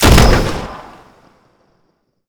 sci-fi_weapon_rifle_large_shot_03.wav